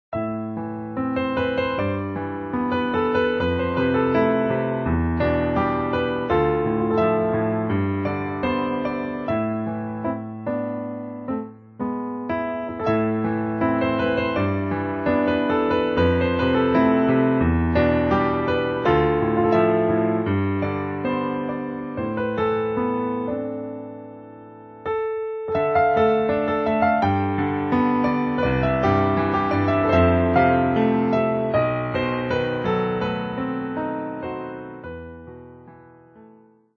ウェブサイトなどで使われた美しいピアノソロの小品を、全曲録り下ろしで20曲収録したベストアルバム。
いつもより心地よく、そして、聴きやすさを重視したピアノの旋律なので、くつろぎのBGMとしても最適です。